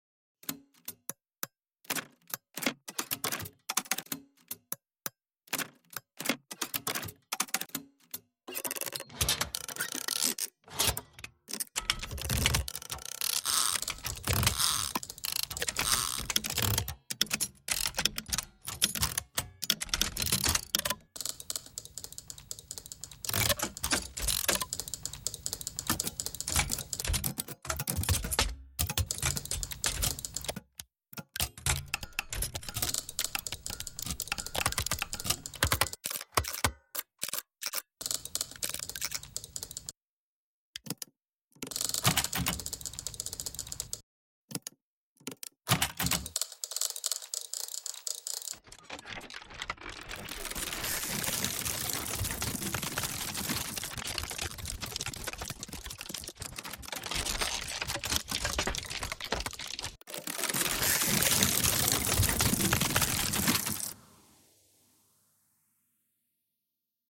Piece based on telegraph sounder and speaking telephone